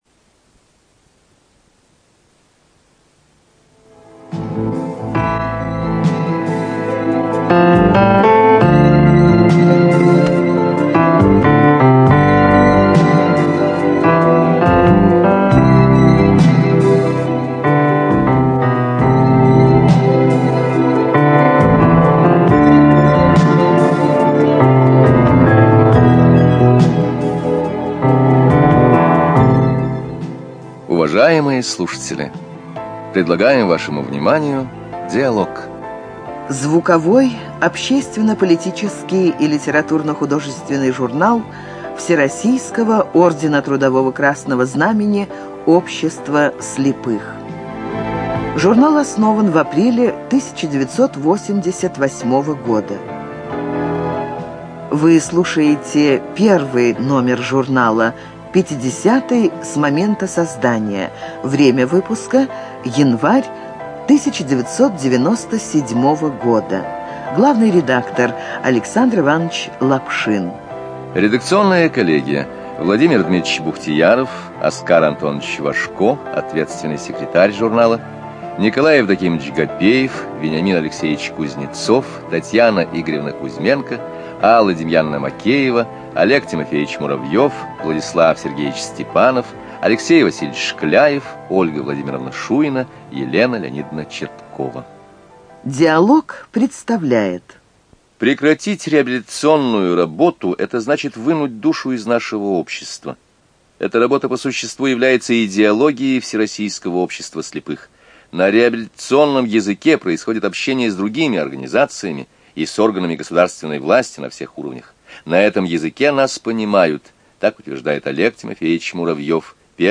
ЖанрРеабилитация, Публицистика, Документальные фонограммы
Студия звукозаписиЛогосвос